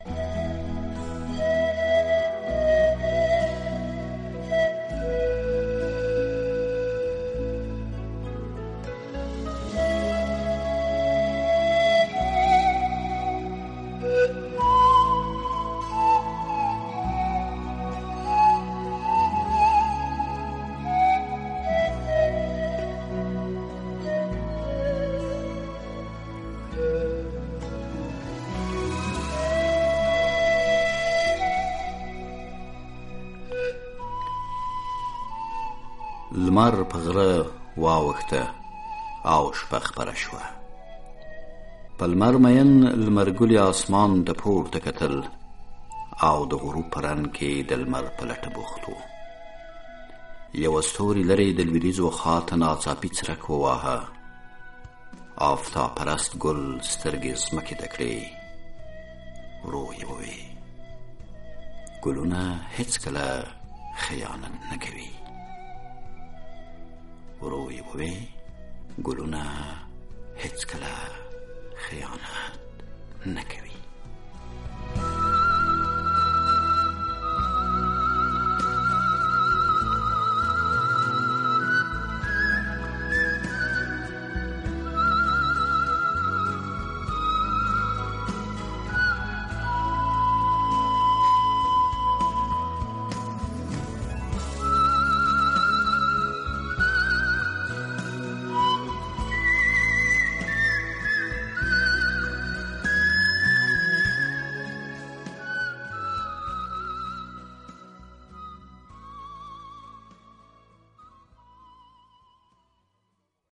د یو ښکلي شعر دکلیمه